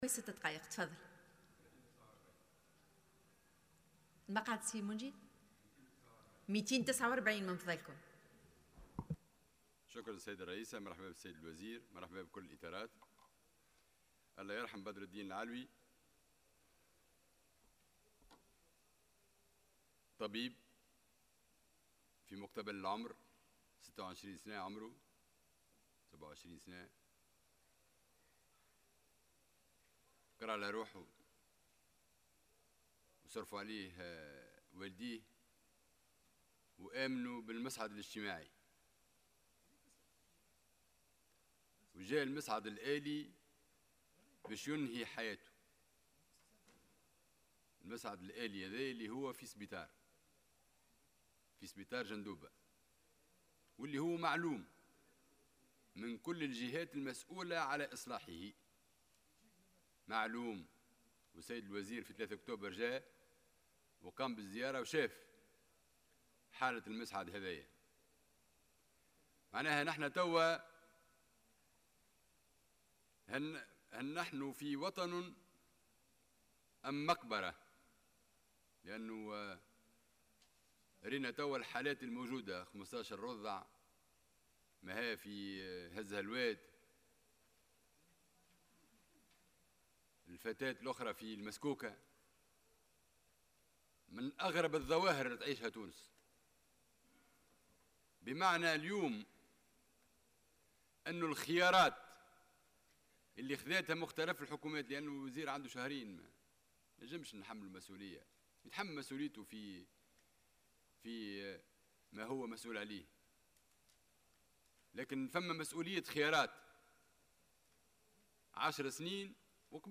أكد النائب منجي الرحوي في مداخلة له اليوم الجمعة على هامش الجلسة العامة المخصصة لمناقشة ميزانية وزارة الصحة لسنة 2021 وجود خيار وقرار بإضعاف الصحة العمومية وتحويل المستشفيات العمومية بالمناطق الداخلية خاصة إلى مذابح وفق قوله.